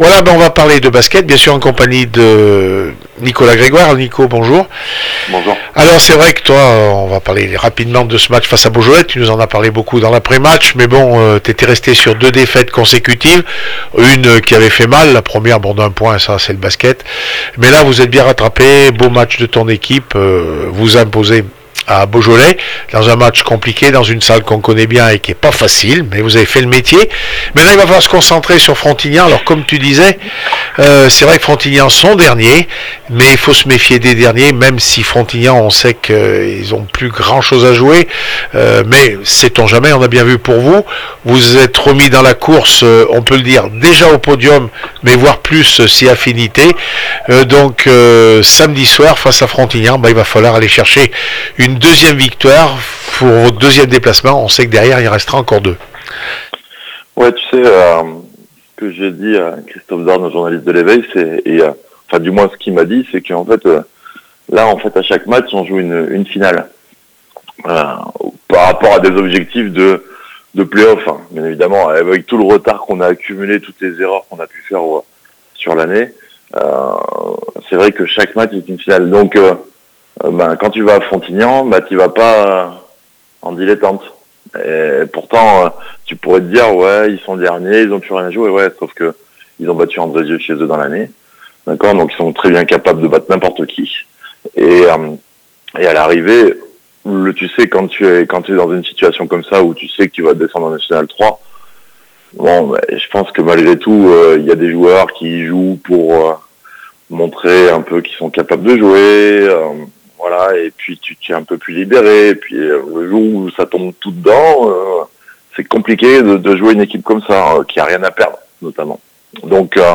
AVANT MATCH REACTION